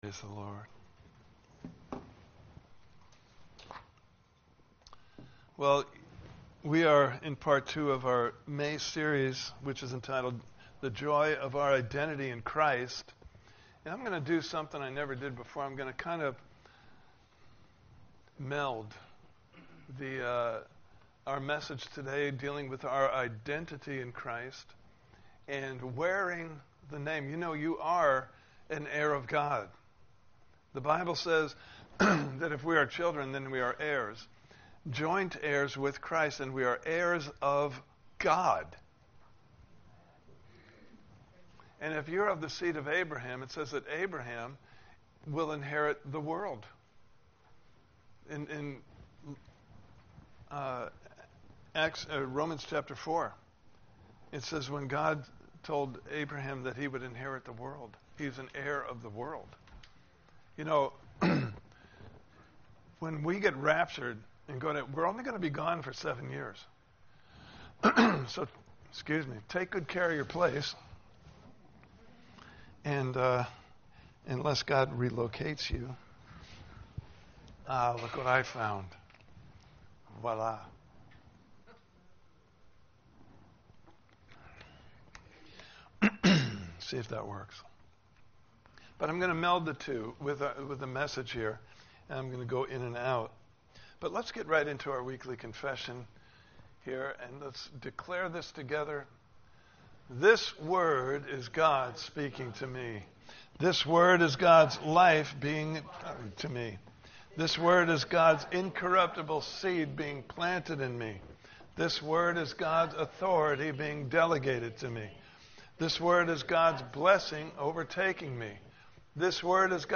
Series: The Joy of Our Identity in Christ! Service Type: Sunday Morning Service « Part 1: All Things Are New (Video) Part 2: The Heir Wears the Name!